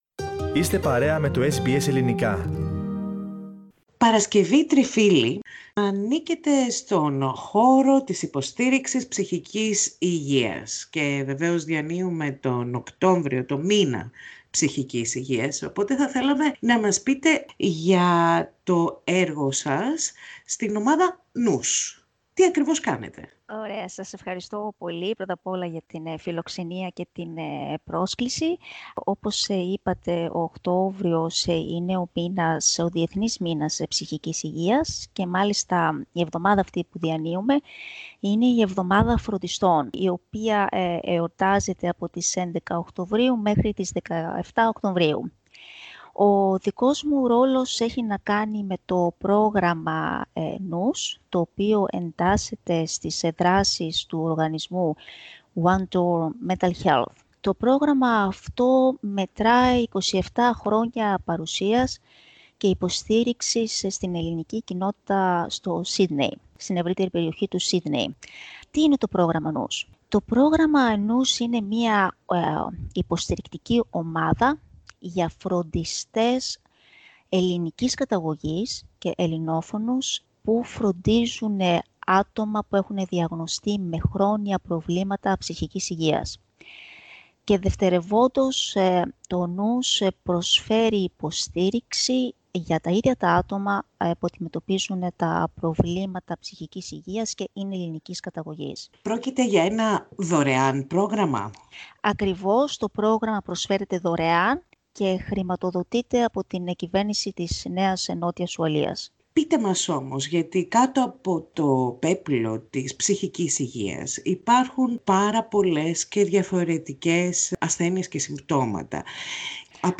This interview is in Greek.